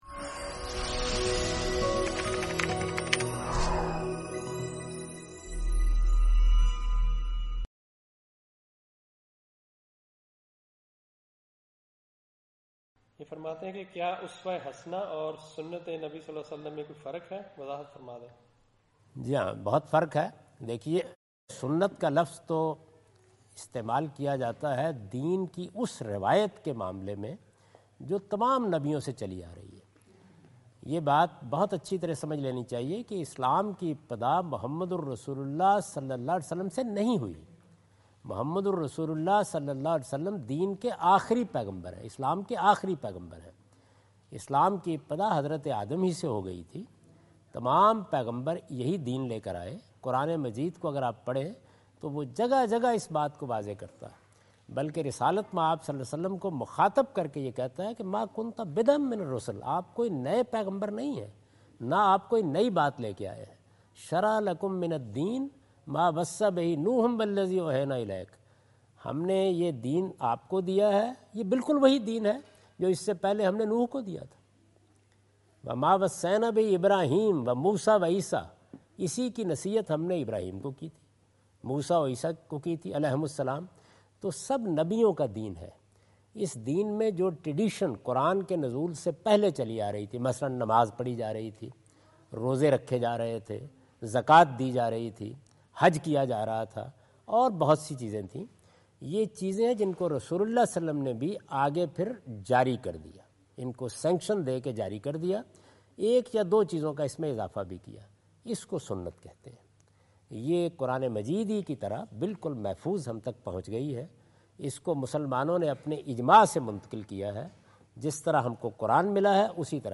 Javed Ahmad Ghamidi answer the question about "Uswah-e-Hasanah and Sunnah" during his visit in Canberra Australia on 03rd October 2015.
جاوید احمد غامدی اپنے دورہ آسٹریلیا کے دوران کینبرا میں "اسوہ حسنہ اور سنتِ رسول" سے متعلق ایک سوال کا جواب دے رہے ہیں۔